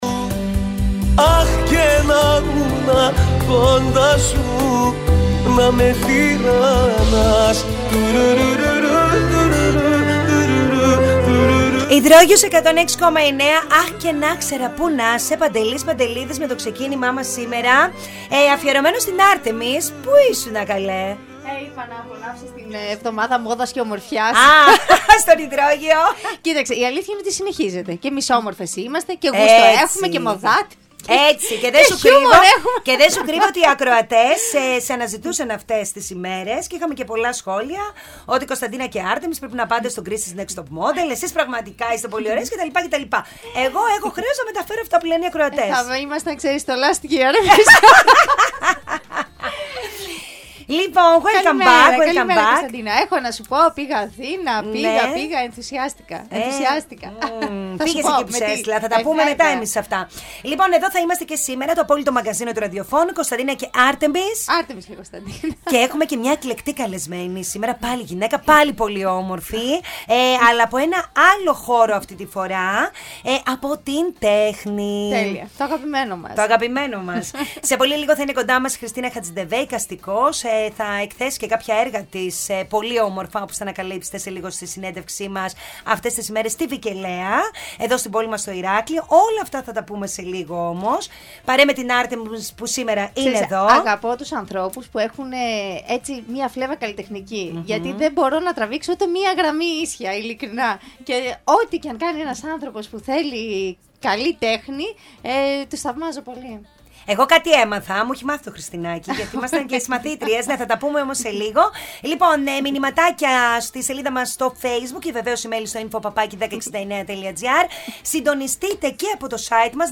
Ακούστε όλη την συνέντευξη της: